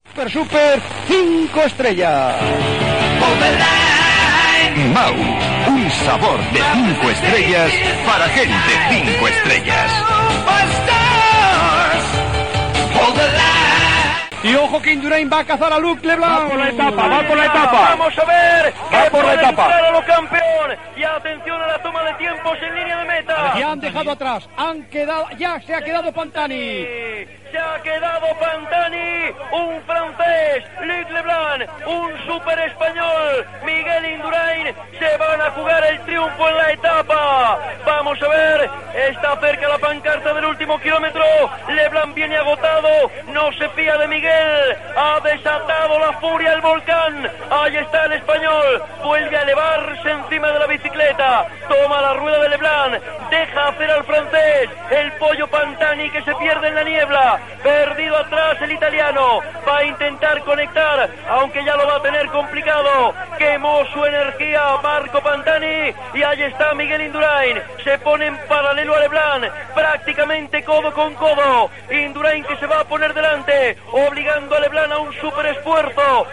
Publicitat i narració del final de l'onzena etapa amb arribada als Pirineus, a l'alt del cim de Hautacam.
Esportiu